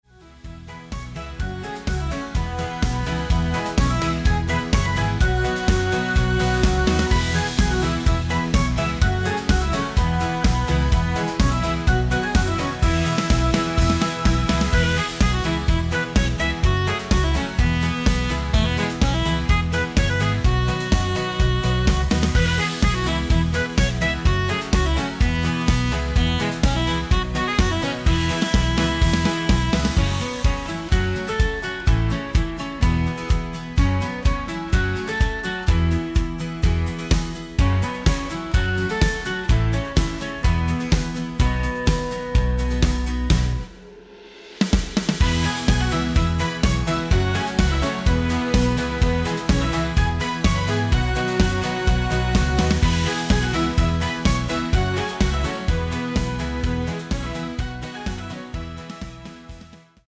Polka, Foxtrott, auch für Parties lustig